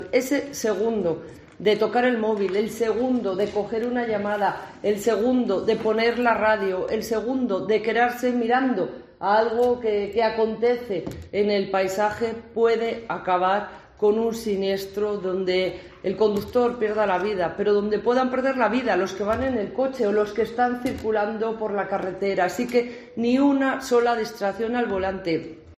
La delegada del Gobierno, Virgina Barcones, ha realizado un llamamiento a la responsabilidad de los conductores para cumplir las normas de seguridad vial.